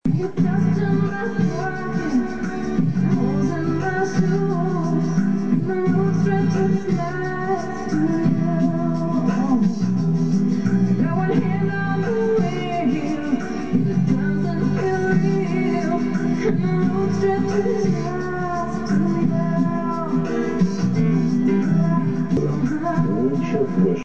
P. S. Песня с радио рекламы